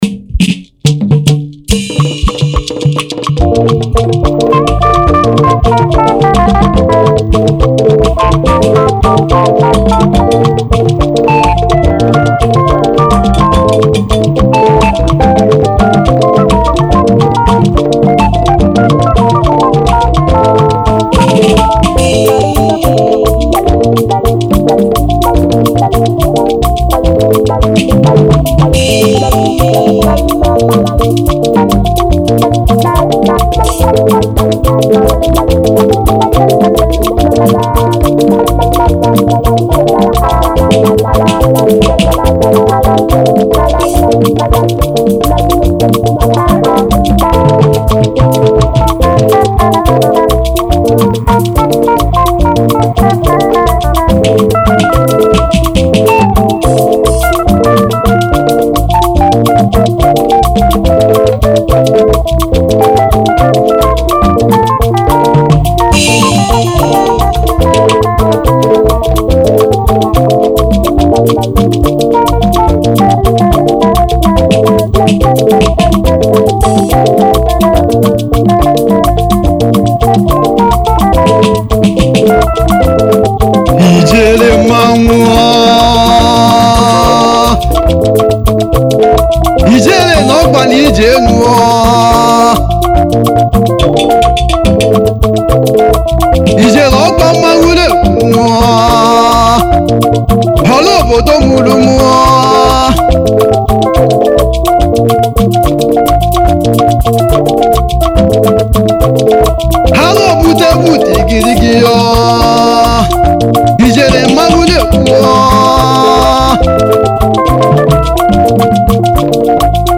Category : Highlife
Highlife Traditional